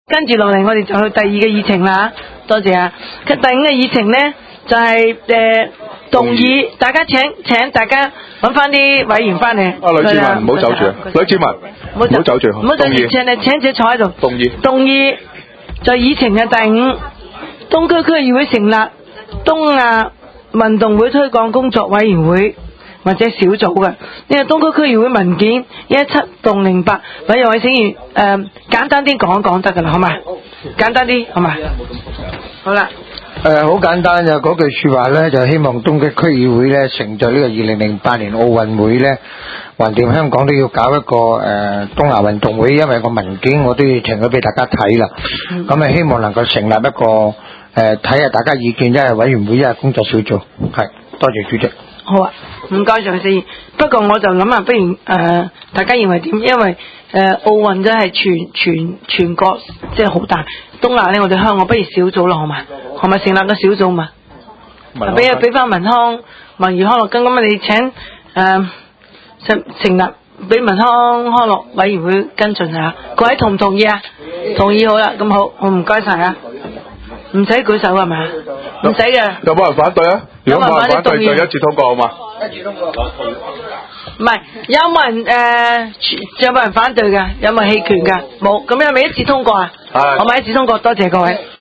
東區區議會會議室